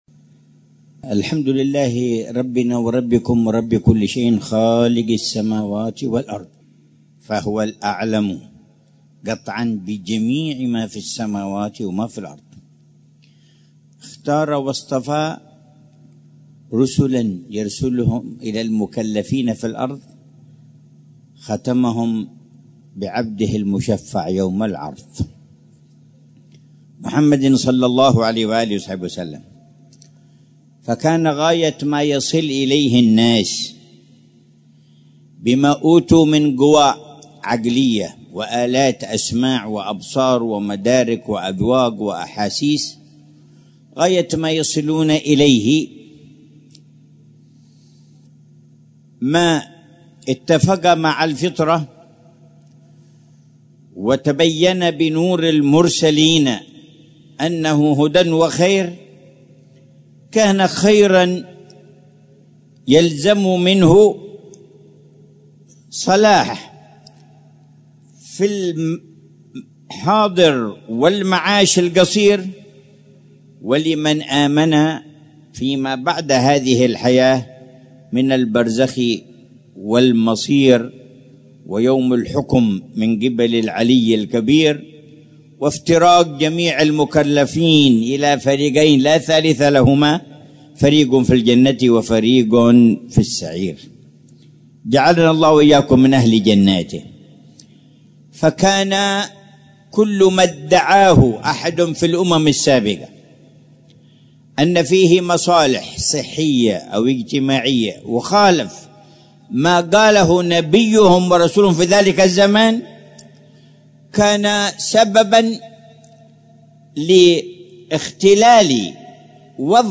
محاضرة العلامة الحبيب عمر بن محمد بن حفيظ في جلسة الجمعة الشهرية الـ58، في ساحة جامع الرحبة، باستضافة حارتي الرحبة والبدور بمدينة تريم، ليلة السبت 21 شوال 1446هـ، بعنوان: